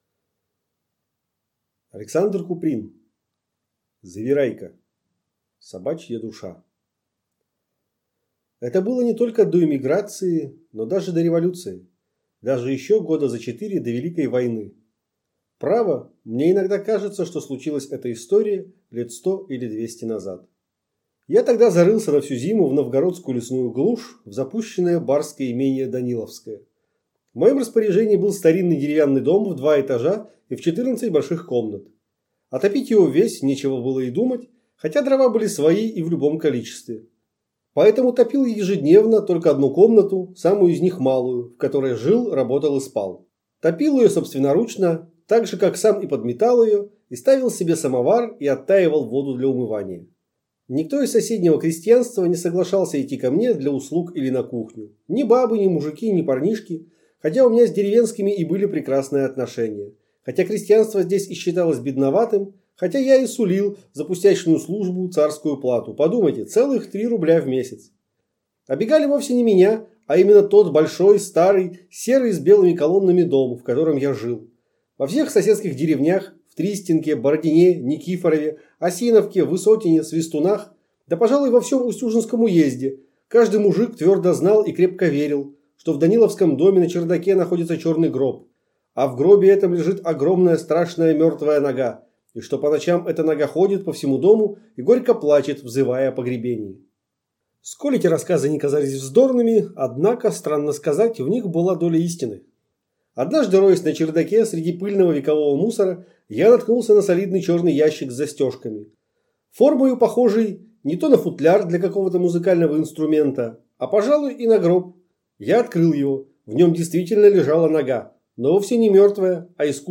Аудиокнига Завирайка | Библиотека аудиокниг